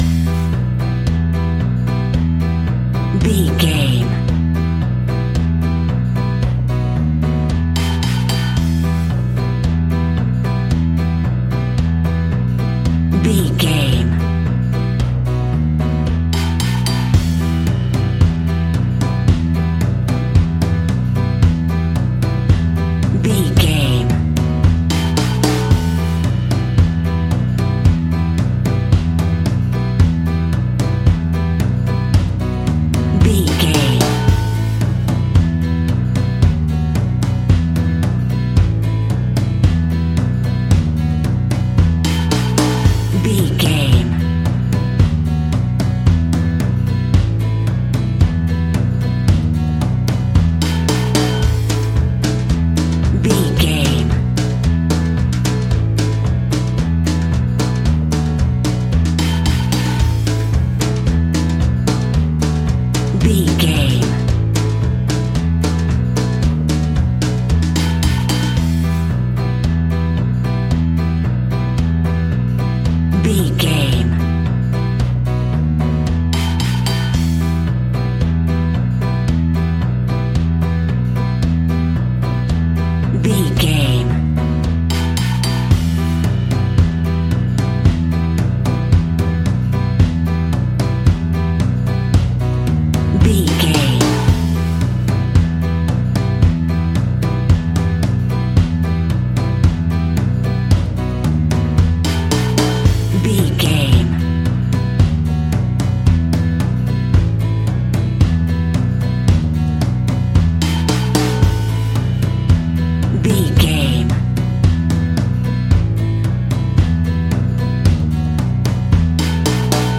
Aeolian/Minor
childrens music
instrumentals
fun
childlike
cute
happy
kids piano